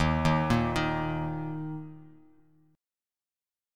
D#5 Chord
Listen to D#5 strummed